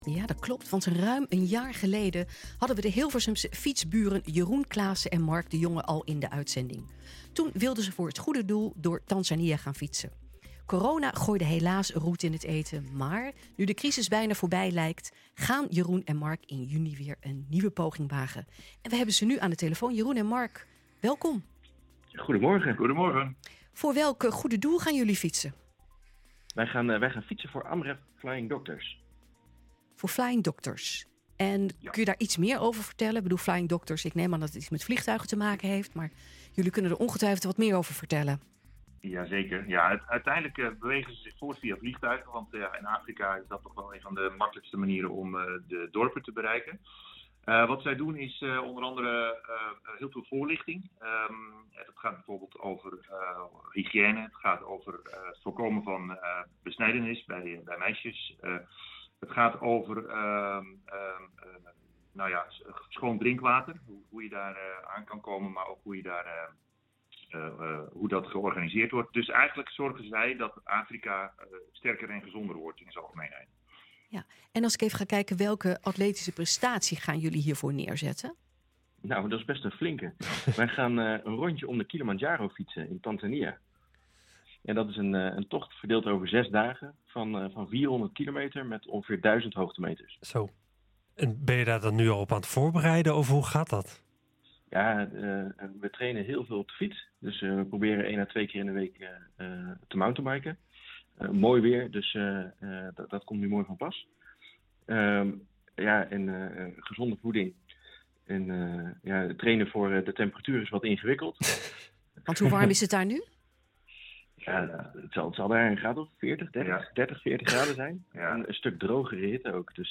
En we hebben ze nu aan de telefoon.